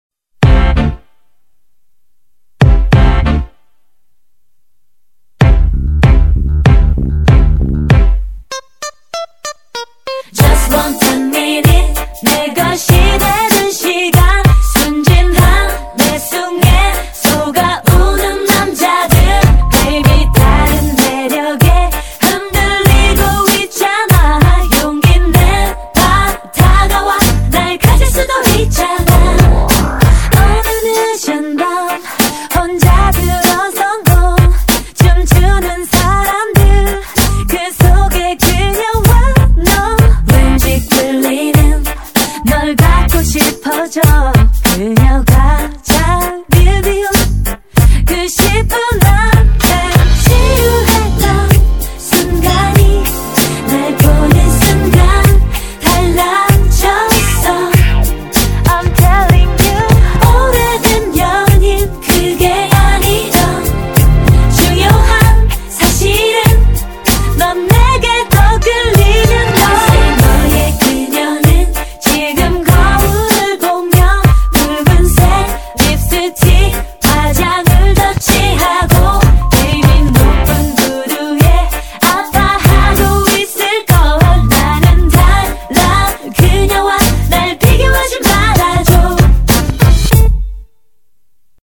BPM96--1
Audio QualityPerfect (High Quality)